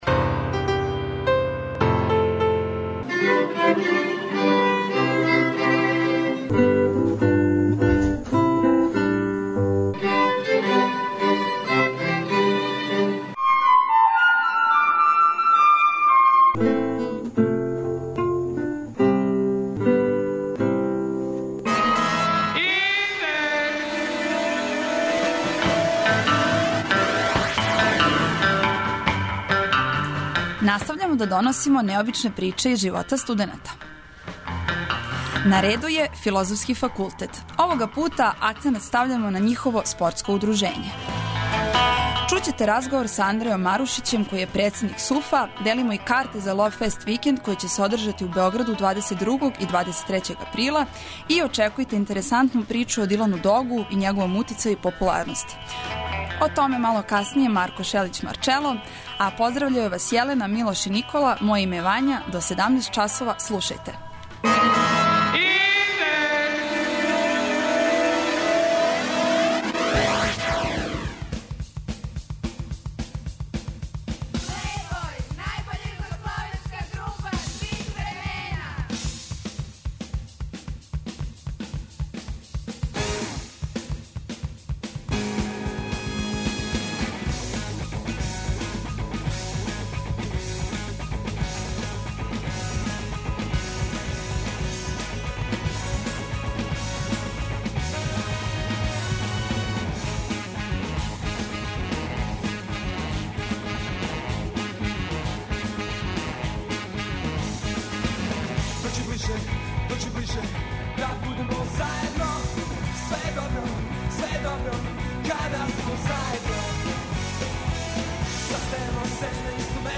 О Дилану Догу и његовом утицају и популарности говориће Марко Шелић Марчело!